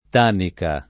tanca [ t #j ka ] s. f.